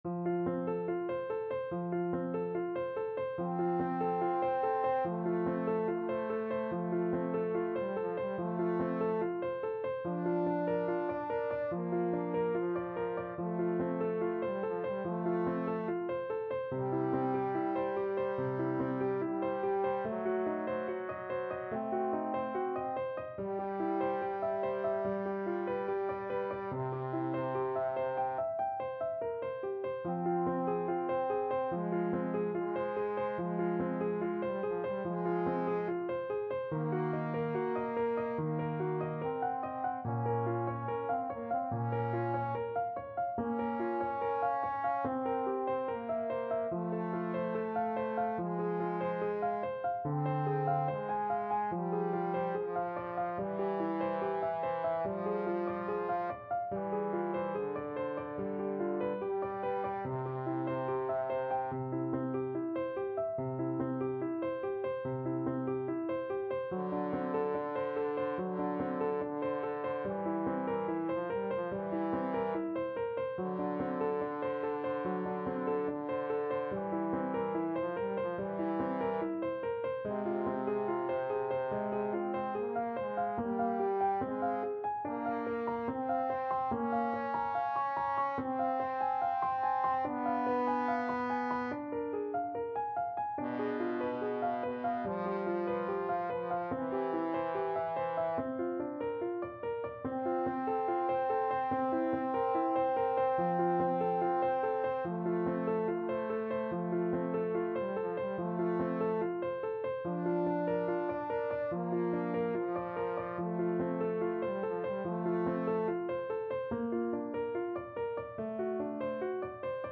Andante =72
Trombone Duet  (View more Intermediate Trombone Duet Music)
Classical (View more Classical Trombone Duet Music)